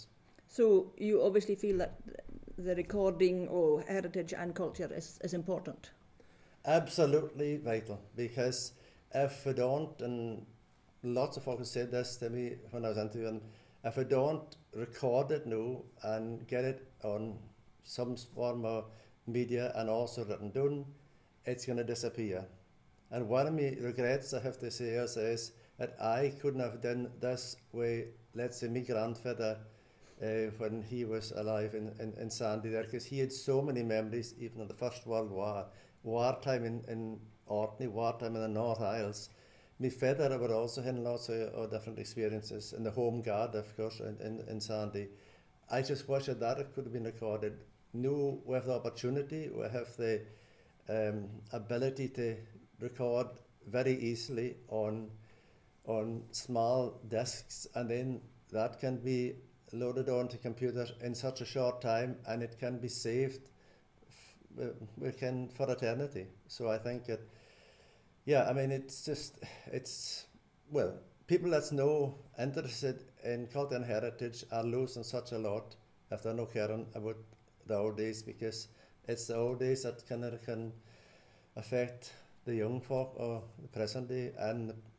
The recollections were gathered through face-to-face interviews across generations of folk from a variety of backgrounds.